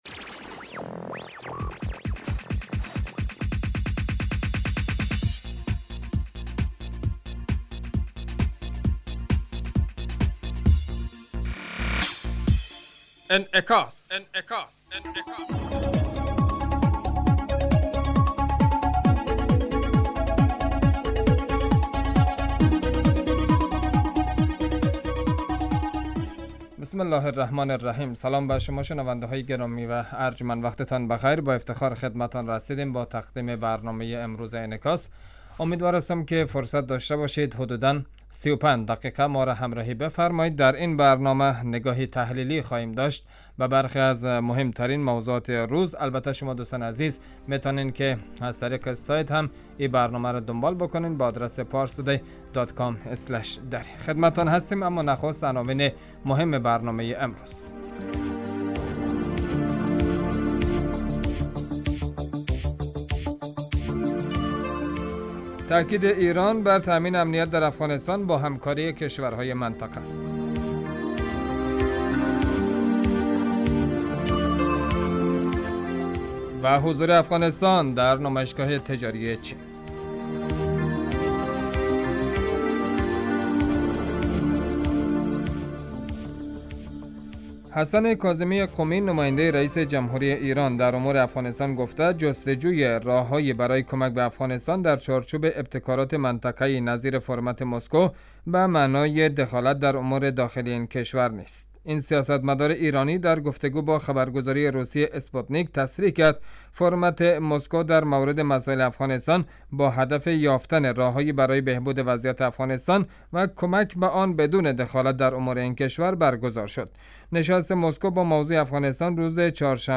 برنامه انعکاس به مدت 35 دقیقه هر روز در ساعت 06:50 بعد ظهر (به وقت افغانستان) بصورت زنده پخش می شود.